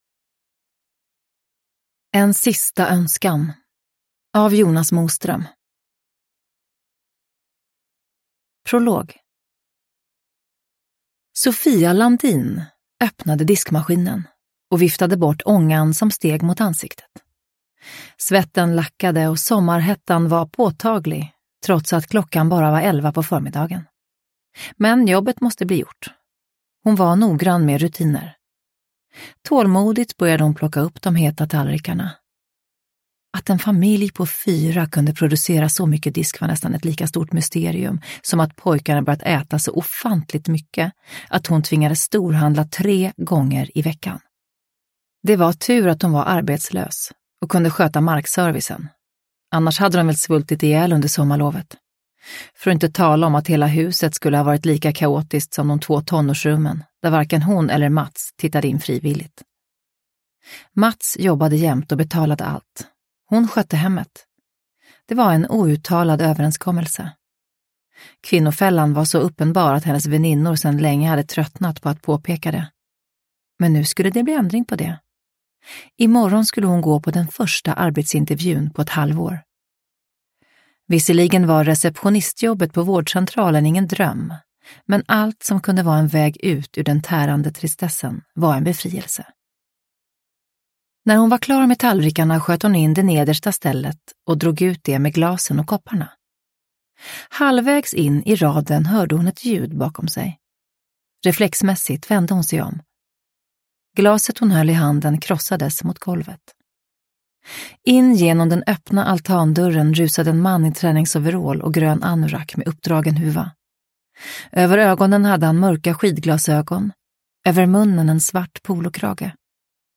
En sista önskan – Ljudbok – Laddas ner
Uppläsare: Eva Röse